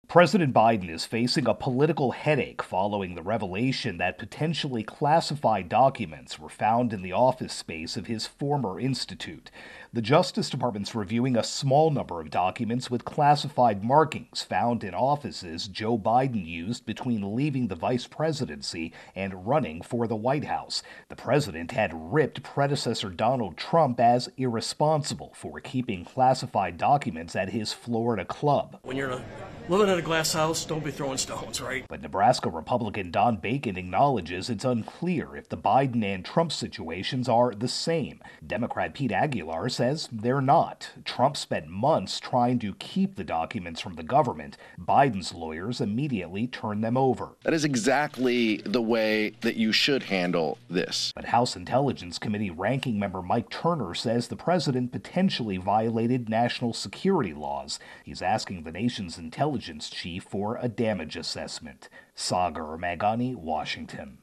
reports on Biden-Classified Documents.